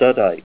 Help on Name Pronunciation: Name Pronunciation: Studtite
Say STUDTITE